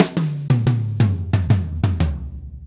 Drumset Fill 19.wav